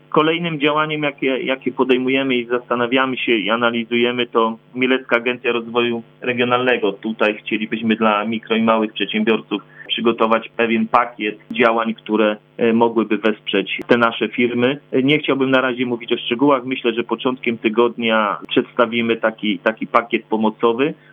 Dlatego chcielibyśmy również wesprzeć poprzez nasze działania firmy działające w naszym regionie, dodaje prezydent Mielca.